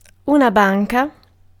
Ääntäminen
Ääntäminen Tuntematon aksentti: IPA: /bɑ̃k/ Haettu sana löytyi näillä lähdekielillä: ranska Käännös Ääninäyte Substantiivit 1. banca {f} 2. banco {m} Suku: f .